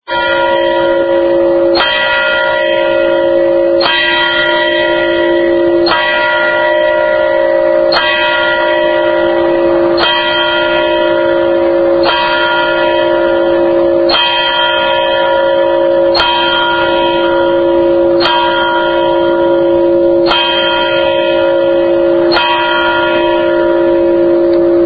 Single Ring (Dong – Dong)